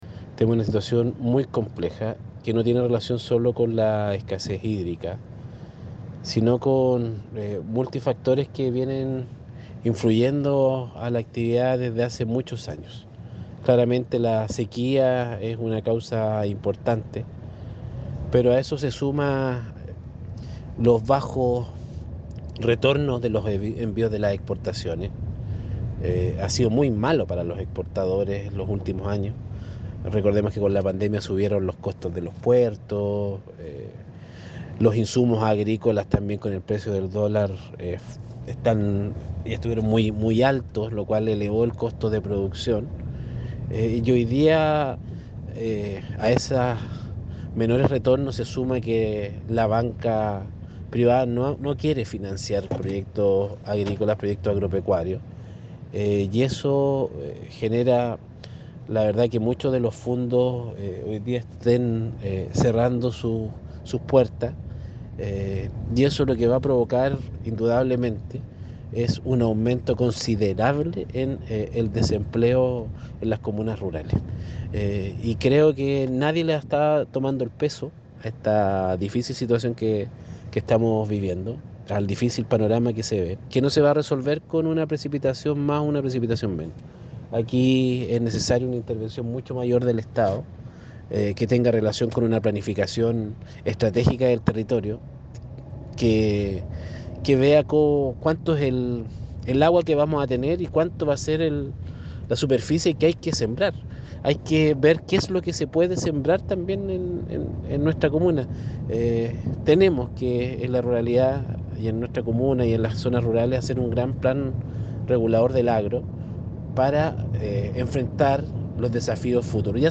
ALCALDE-HERRERA-1-Cristian-Herrera-Pena.mp3